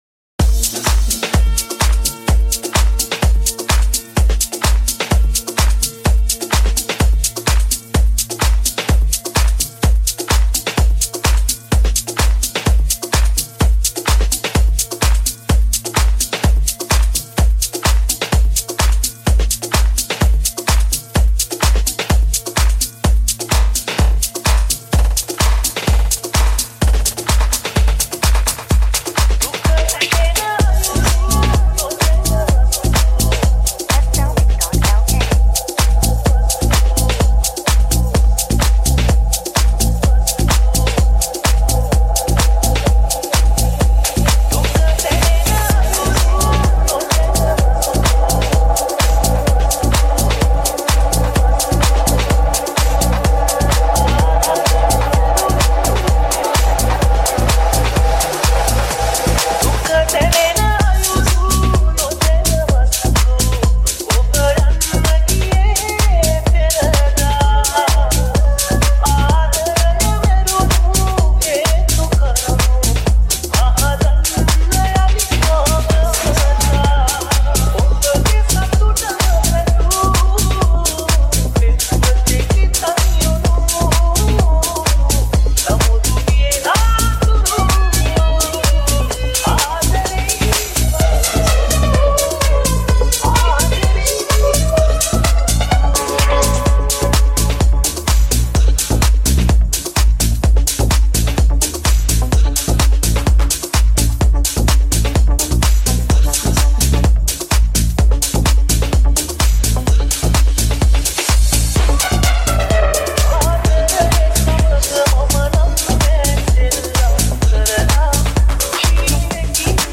Tech House Remix